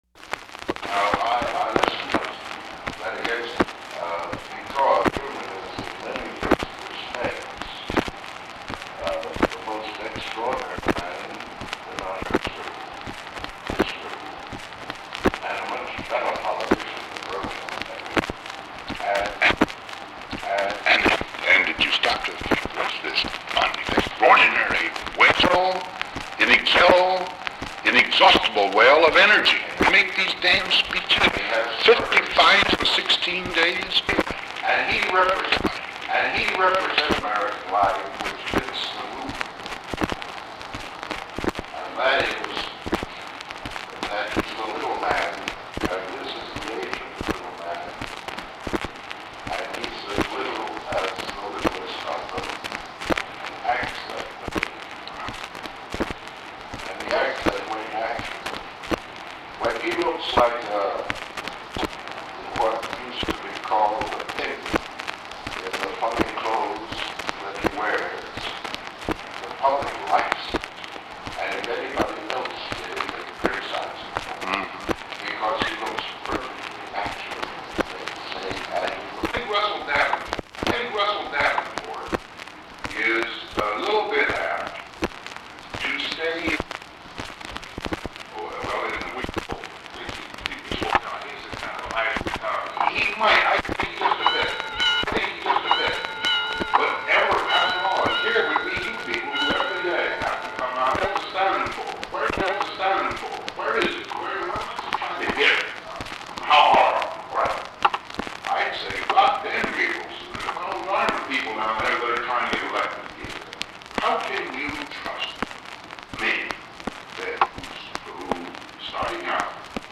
The recording begins with the conversation already in progress.
Secret White House Tapes